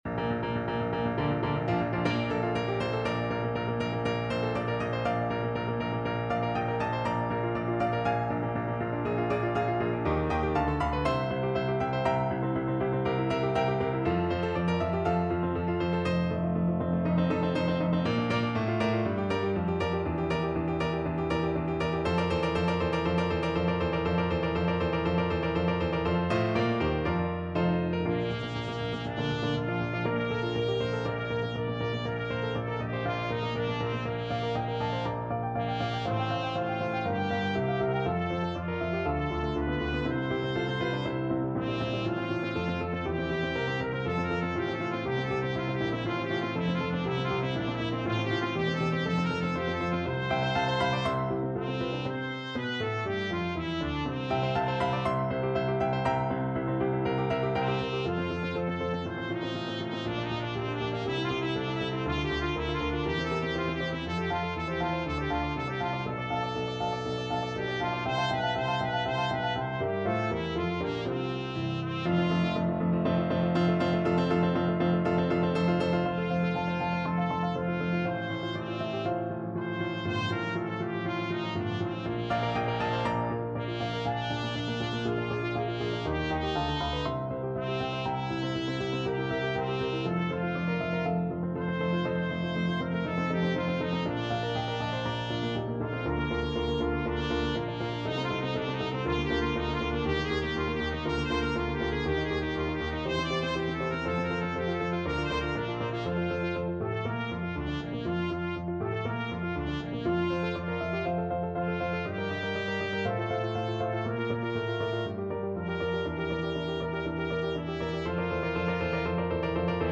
From Messiah Trumpet version
Free Sheet music for Trumpet
Trumpet
~ = 100 Molto allegro =c.120
Bb major (Sounding Pitch) C major (Trumpet in Bb) (View more Bb major Music for Trumpet )
4/4 (View more 4/4 Music)
A4-D6
Classical (View more Classical Trumpet Music)